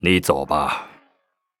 文件 文件历史 文件用途 全域文件用途 Vanjelis_fw_01.ogg （Ogg Vorbis声音文件，长度1.6秒，117 kbps，文件大小：23 KB） 源地址:地下城与勇士游戏语音 文件历史 点击某个日期/时间查看对应时刻的文件。 日期/时间 缩略图 大小 用户 备注 当前 2018年5月13日 (日) 02:56 1.6秒 （23 KB） 地下城与勇士  （ 留言 | 贡献 ） 分类:范哲利斯 分类:地下城与勇士 源地址:地下城与勇士游戏语音 您不可以覆盖此文件。